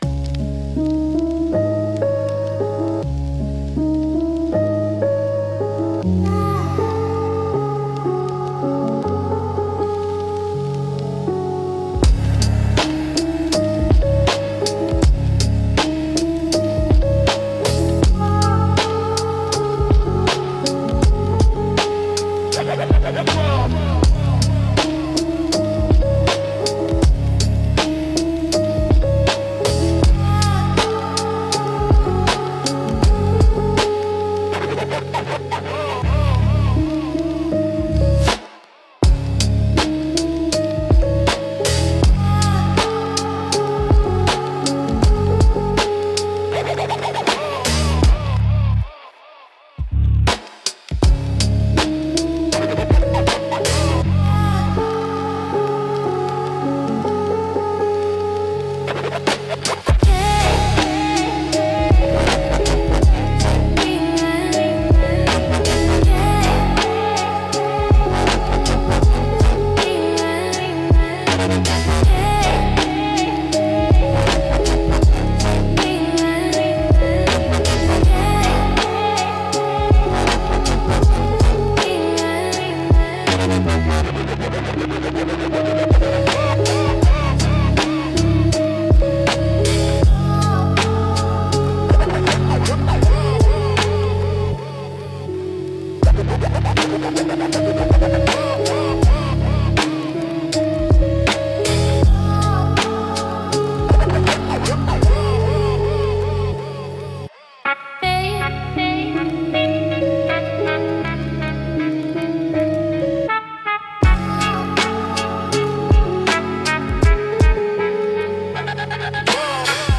Instrumental - Drifters Under Dystopian Light